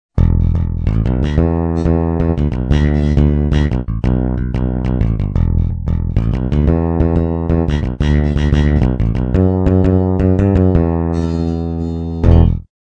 I file MIDI sono le take originali così come sono state registrate con il guitar synth: non c'è stato nessun editing "a posteriori".
Bass Demo1 (MIDI) (
mp3) - registrato con pitch bend=2
GuitarSynthBassDemo1.mp3